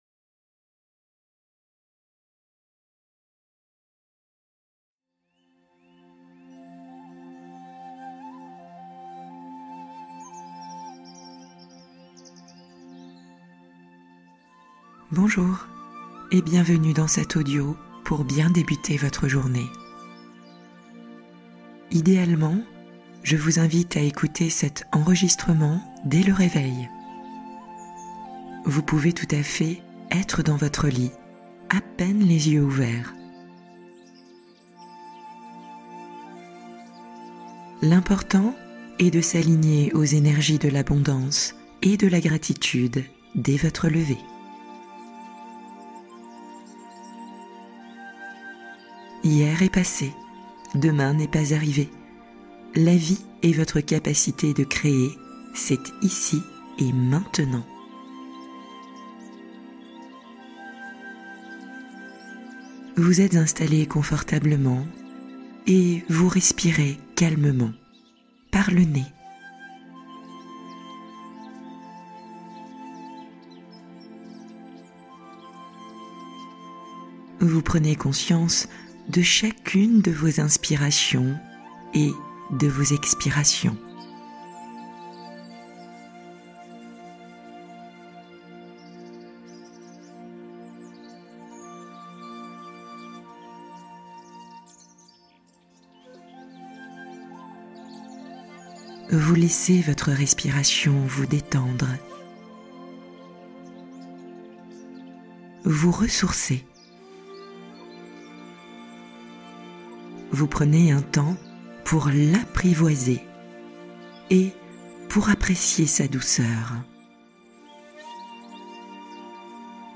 Confiance en la vie retrouvée : relaxation apaisante pour réouvrir l’avenir